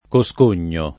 [ ko S k 1 n’n’o ]